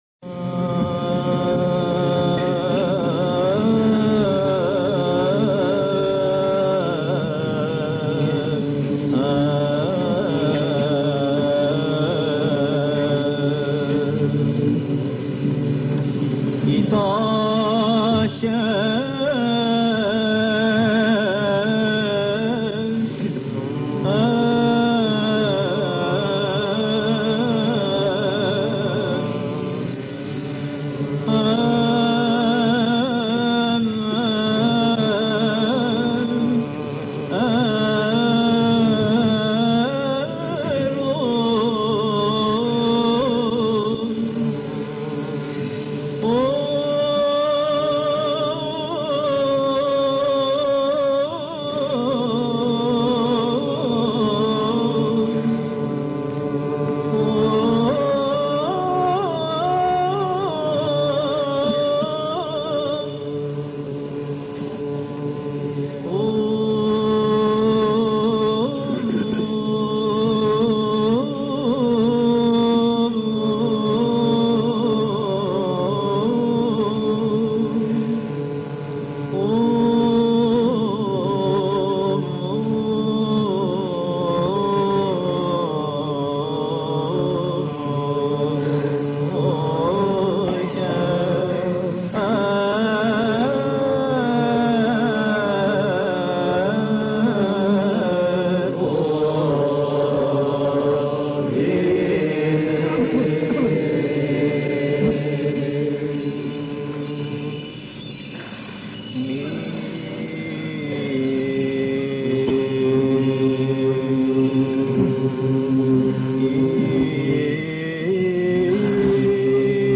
The first choir sing slowly the cherubic hymn:
Mode grave